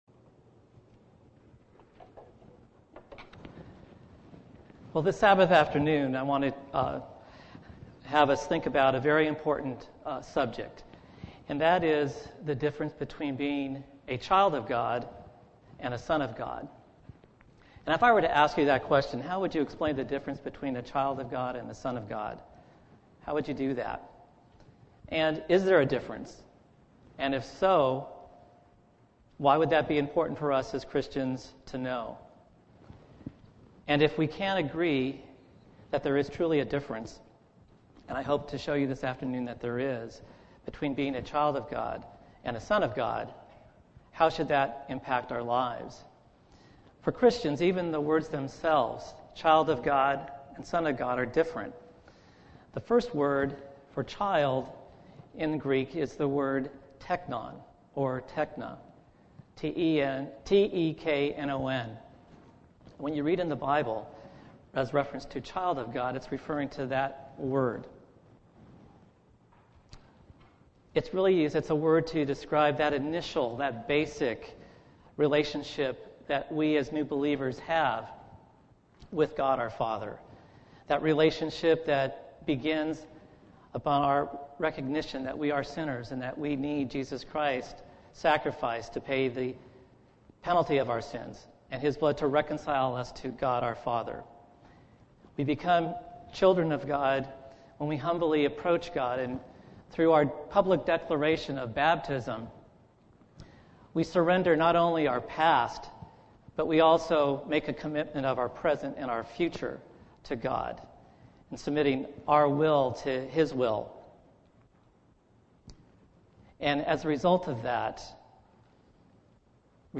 Given in Redlands, CA
UCG Sermon Studying the bible?